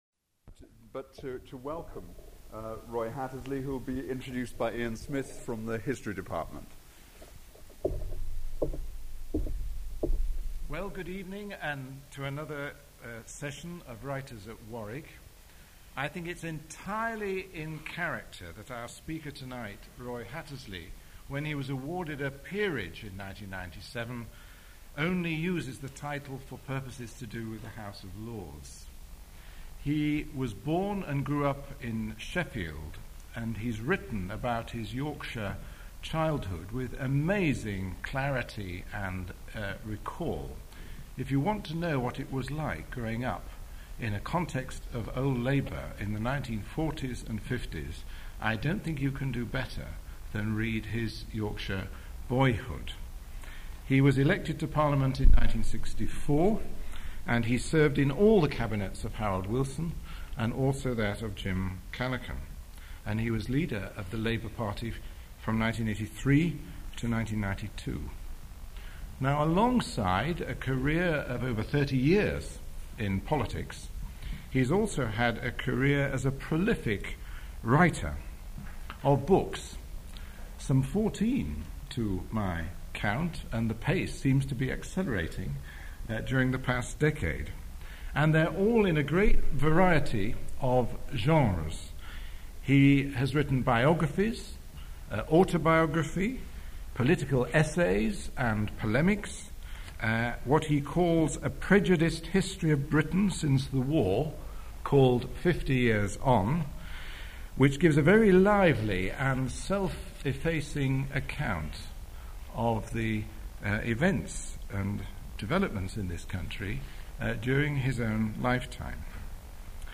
Roy Hattersley speaks at Warwick Arts Centre and discusses his life, his approach to writing and Blood and Fire, his biography of William and Catherine Booth, the founders of the Salvation Army. He answers questions on political novels and on writing as an atheist on religious figures.